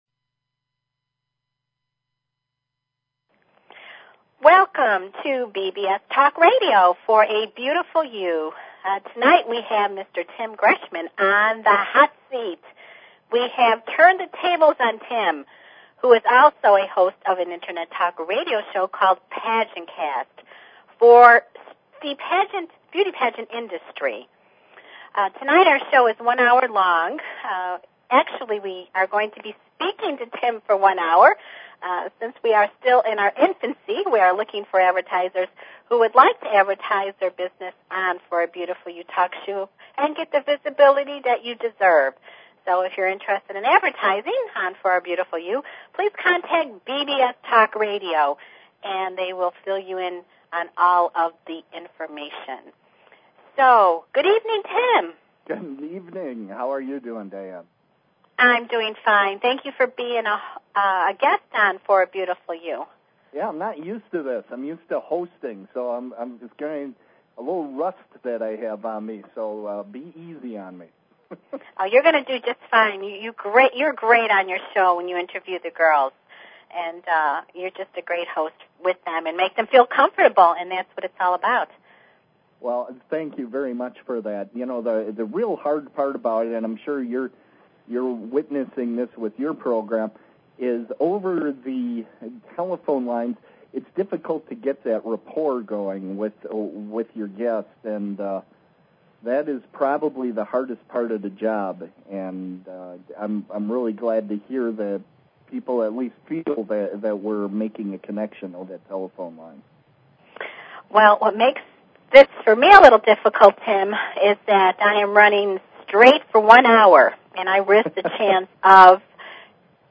Talk Show Episode, Audio Podcast, For_A_Beautiful_You and Courtesy of BBS Radio on , show guests , about , categorized as
Presented by the award winning *Miss *Mrs. U.S. Beauties National Pageant Organization this live weekly one hour show gives a fresh perspective concerning the pageant, fashion, beauty and celebrity industry.